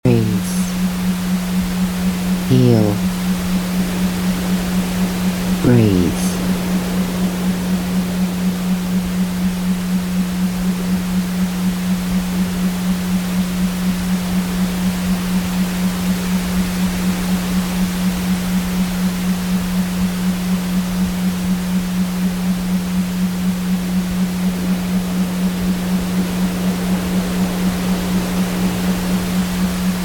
Binaural beats work by sending slightly different sound wave frequencies to each ear, guiding your brain into specific states through brainwave frequency entrainment.
Arthritis Binaural Beat